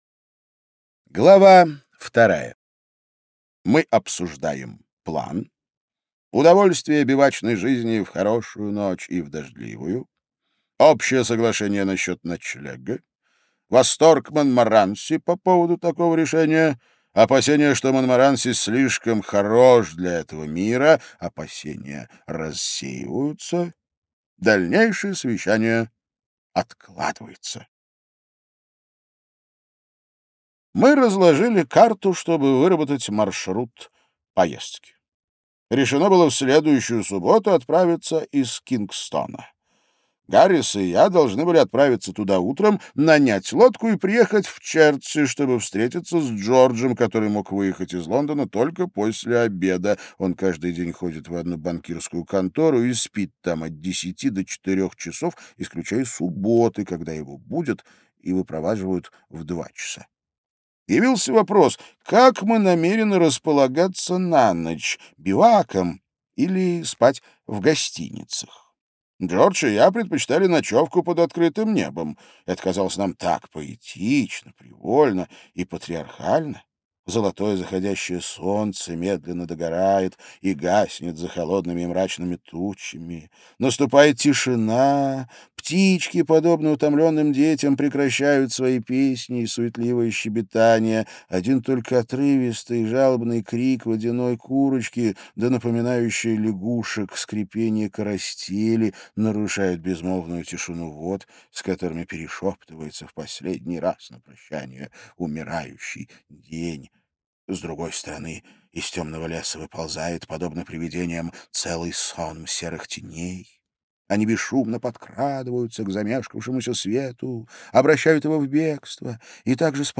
Аудиокнига Трое в одной лодке, не считая собаки - купить, скачать и слушать онлайн | КнигоПоиск